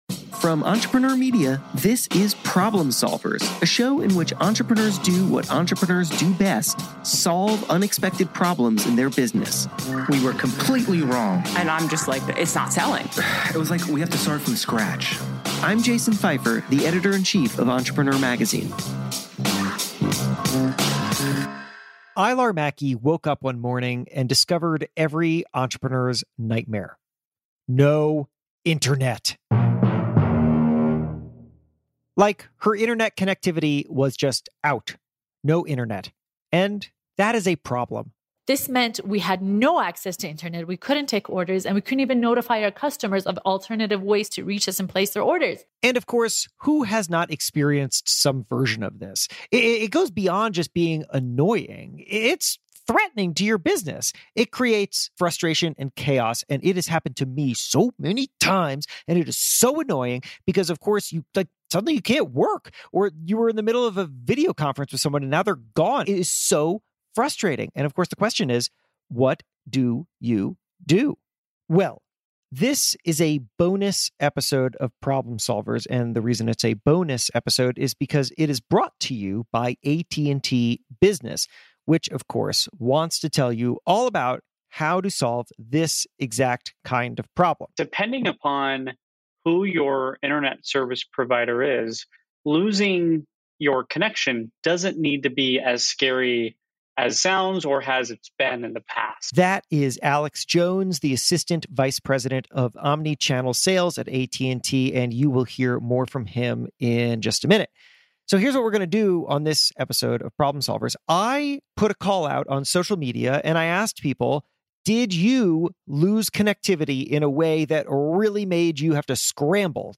An internet connection is the lifeblood of any business, so how can you make sure you never lose it? In this special episode brought to you by AT&T Business, we hear from entrepreneurs who have struggled with lost connectivity and about how you can solve the problem.